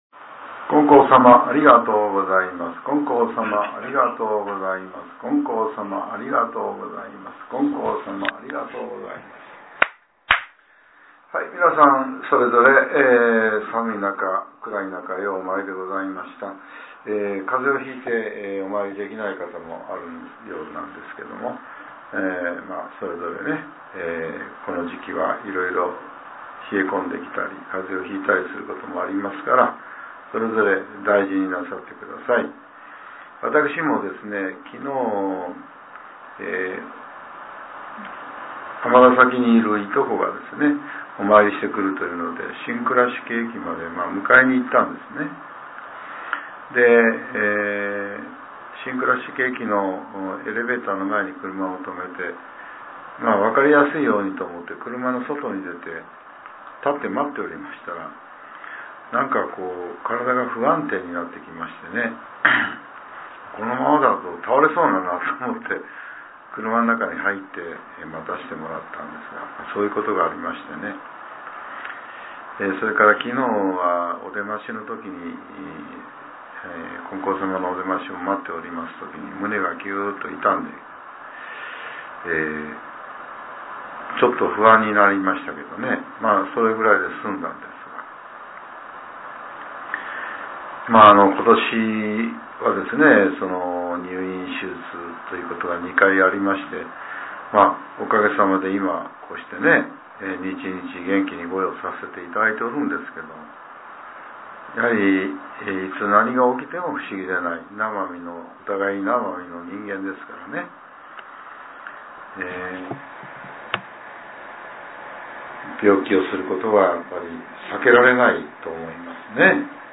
令和６年１２月２１日（朝）のお話が、音声ブログとして更新されています。